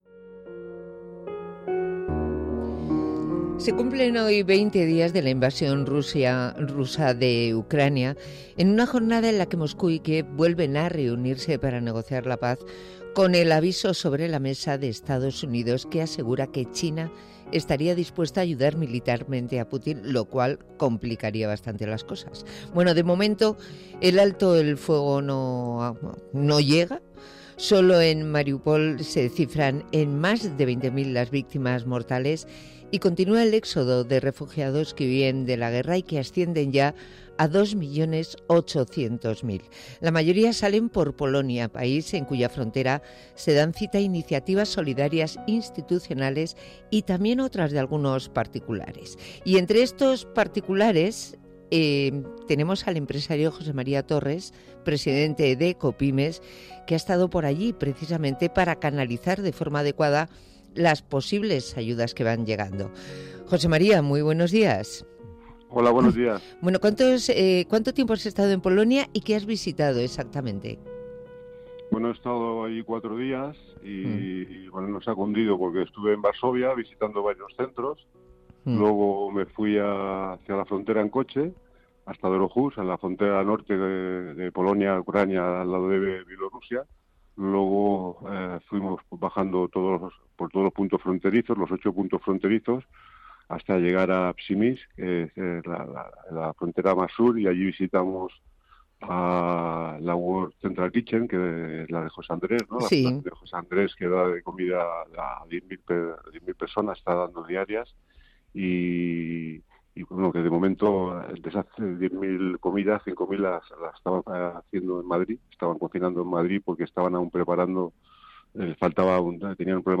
Compartimos la entrevista del 15 de marzo en Onda Madrid, en el programa Buenos días Madrid